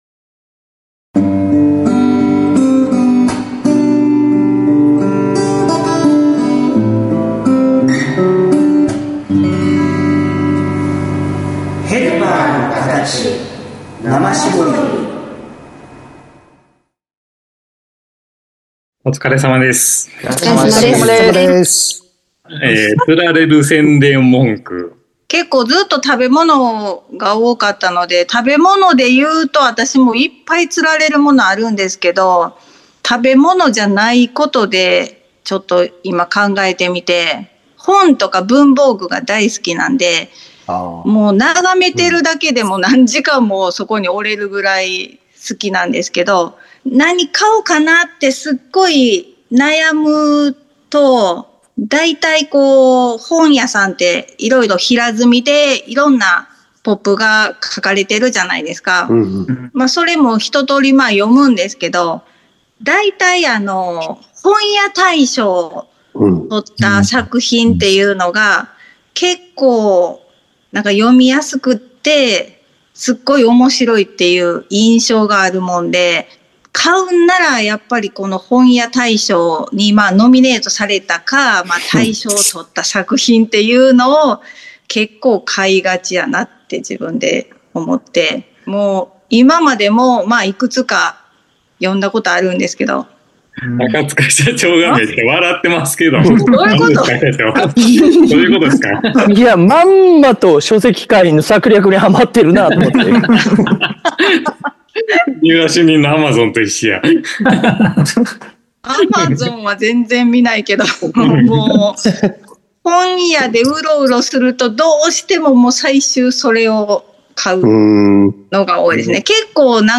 「思わず釣られる宣伝文句」 にまつわるクロストーク。